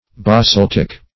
Ba*salt"ic